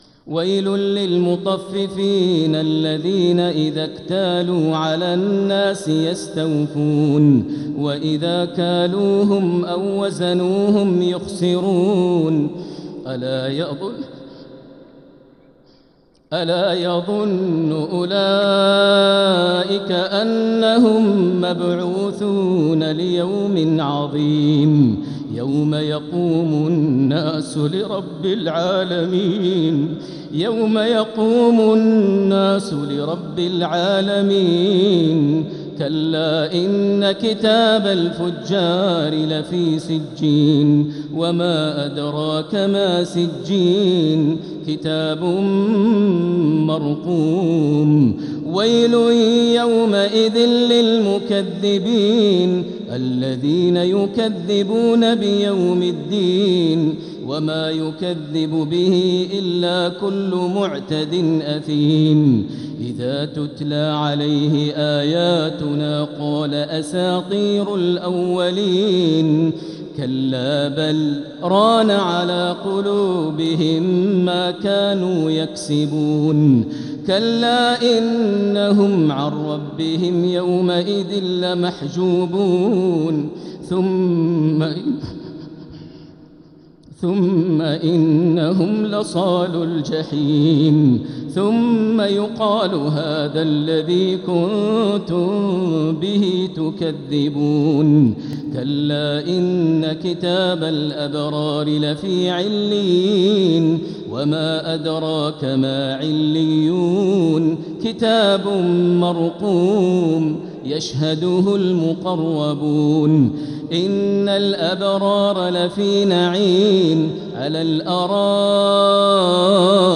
سورة المطففين | مصحف تراويح الحرم المكي عام 1446هـ > مصحف تراويح الحرم المكي عام 1446هـ > المصحف - تلاوات الحرمين